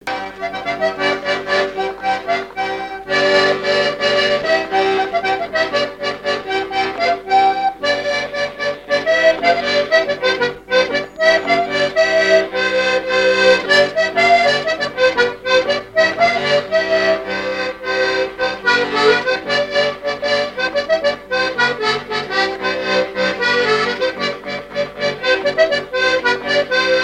branle : courante, maraîchine
danses à l'accordéon diatonique et chansons
Pièce musicale inédite